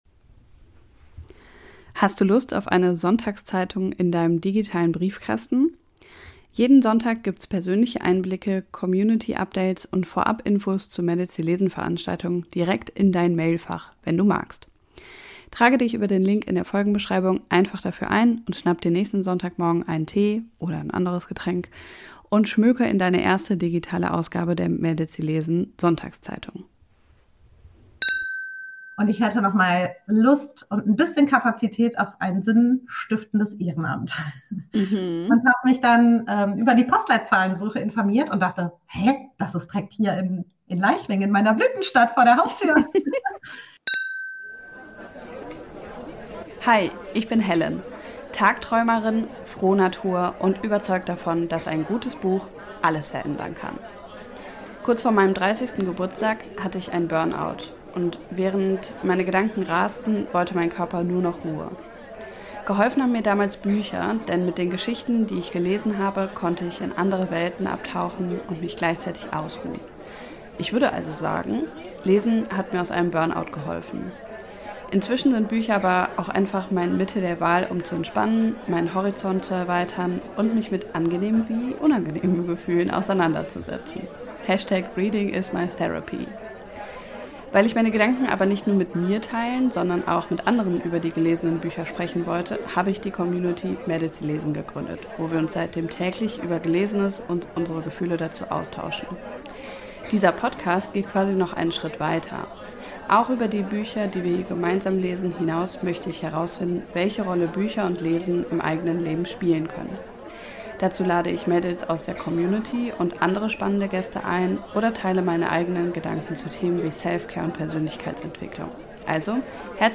Live-Podcast vom 5. Mädels, die lesen.-Geburtstag - Mädels, die lesen. - Podcast